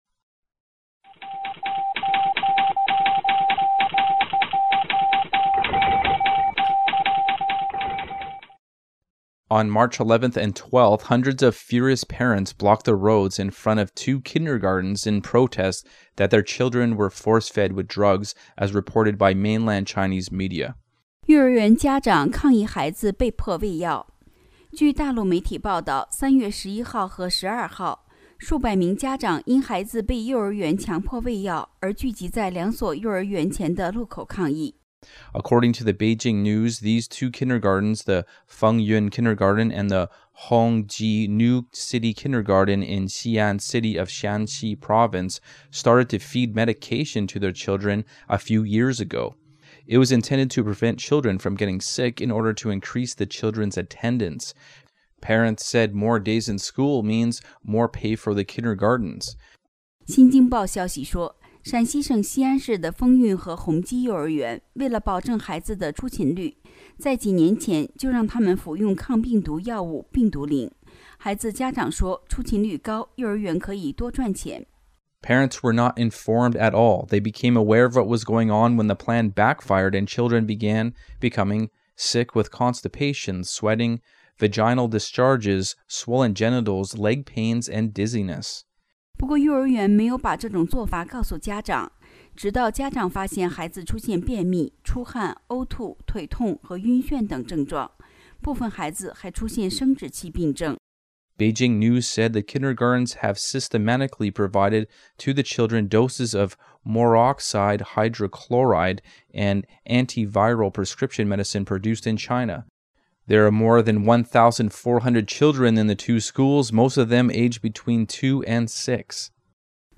Bilingual China-related weekly news
128kbps Stereo